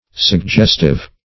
Suggestive \Sug*gest"ive\, a.